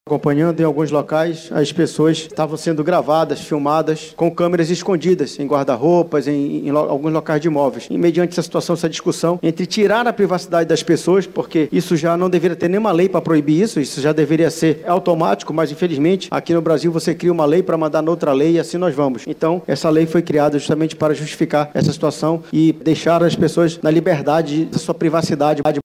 A proposta surgiu após ocorrências envolvendo câmeras ocultas em imóveis de hospedagem, e tem como objetivo garantir a privacidade dos hóspedes, destaca o autor do Projeto.